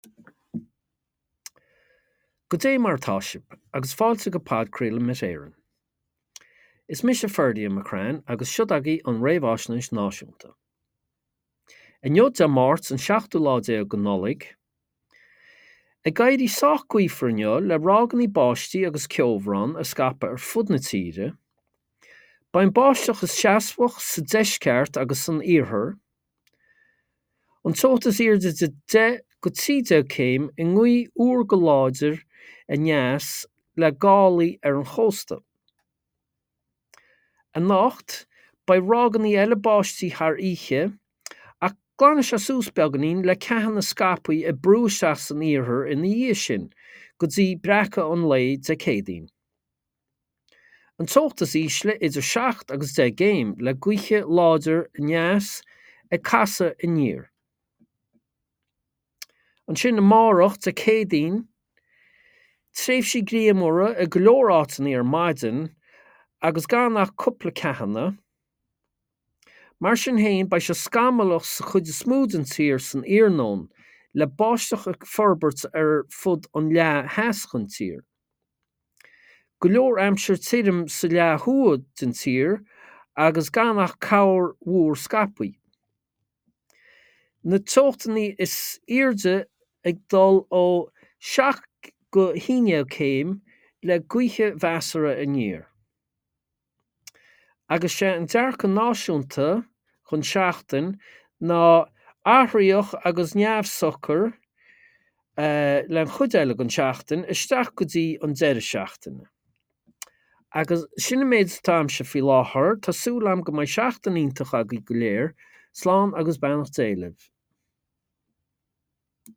Weather Forecast from Met Éireann / Podchraoladh Met Éireann 17/12/24